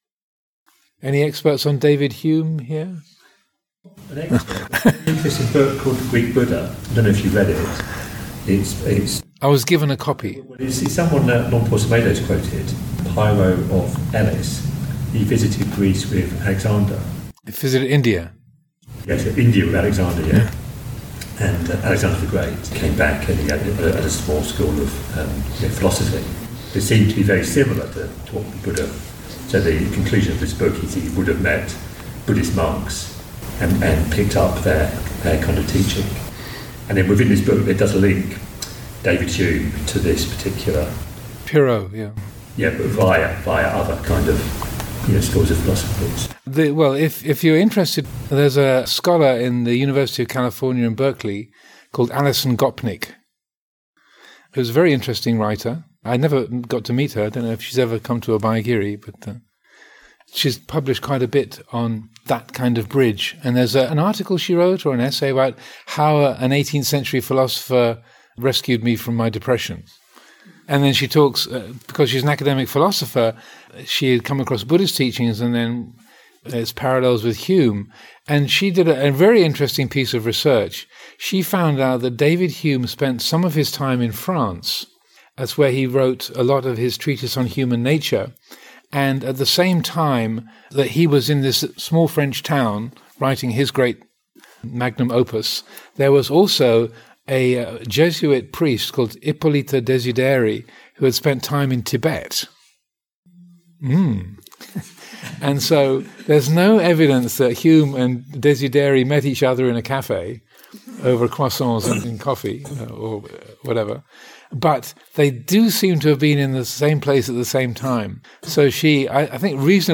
5. Discussion about possible connections between Western philosopy and Buddhism.